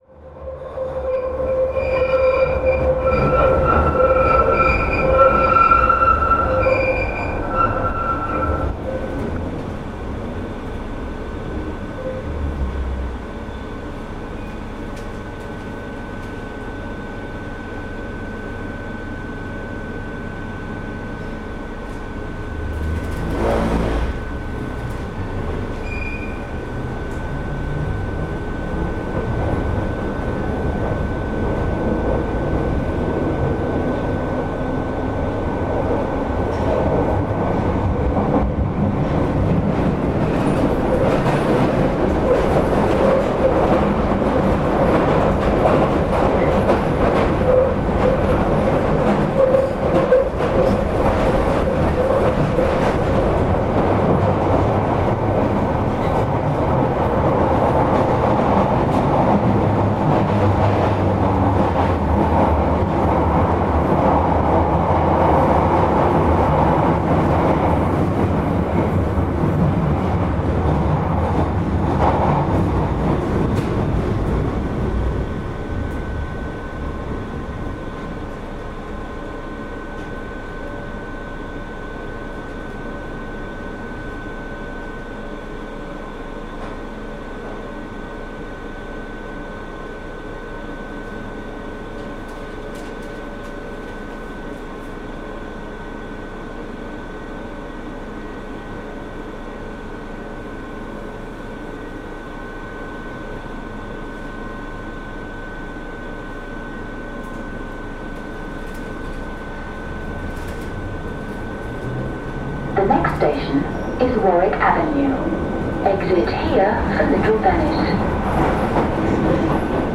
Field recording from the London Underground by Cities and Memory.